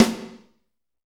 Index of /90_sSampleCDs/Northstar - Drumscapes Roland/DRM_Fast Shuffle/SNR_F_S Snares x